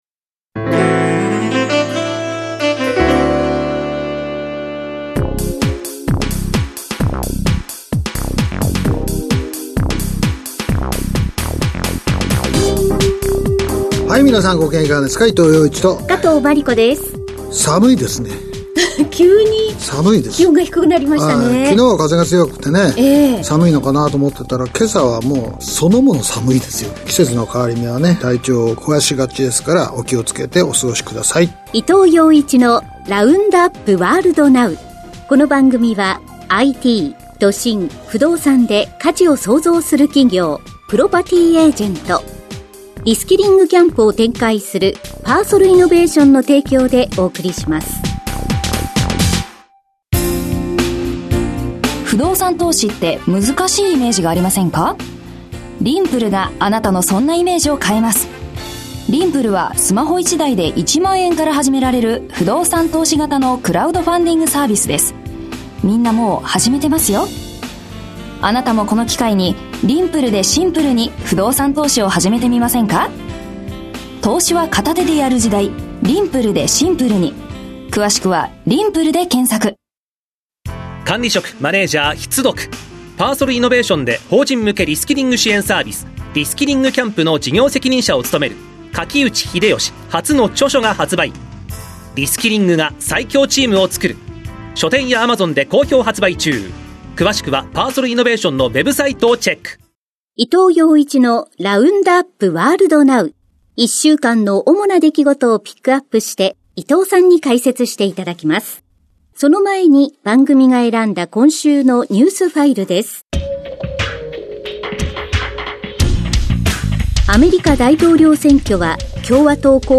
… continue reading 462集单集 # ニューストーク # ニュース # ビジネスニュース # NIKKEI RADIO BROADCASTING CORPORATION